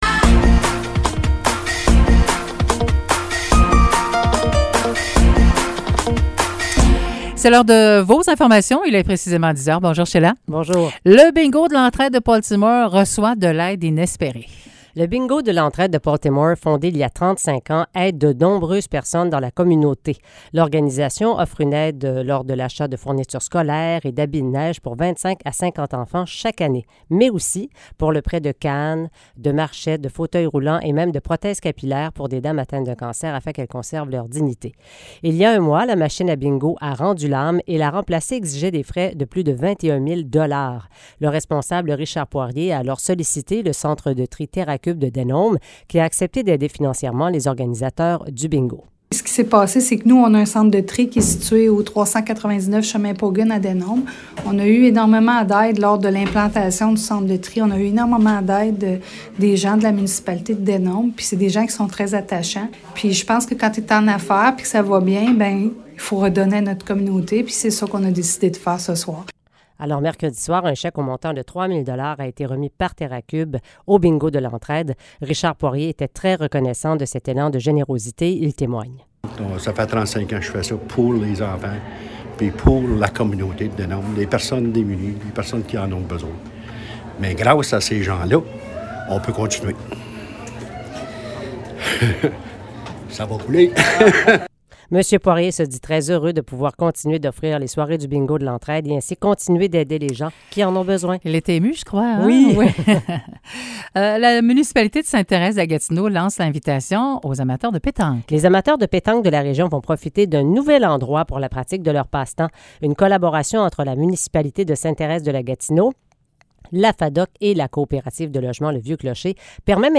Nouvelles locales - 20 octobre 2022 - 10 h